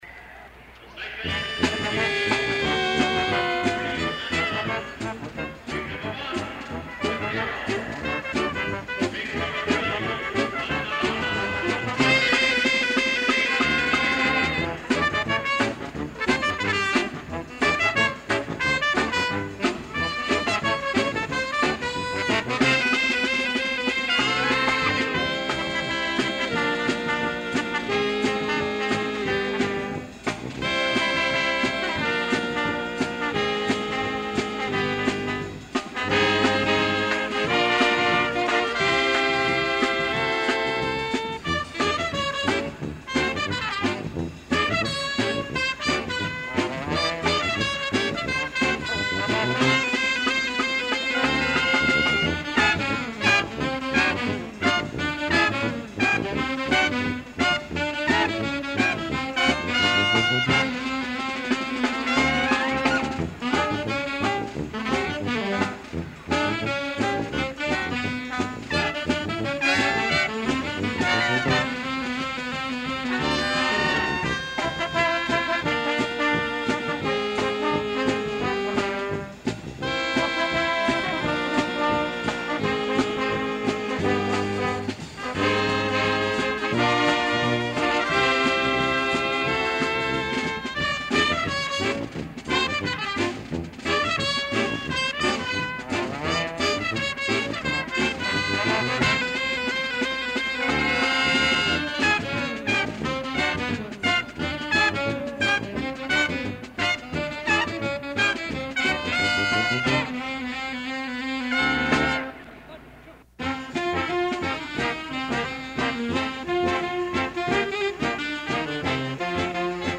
The 21st Annual Aladdin Temple Shrine Circus took place in Columbus, Ohio in April 1971 in the Ohio State Fairgrounds coliseum. The first-rate band of local musicians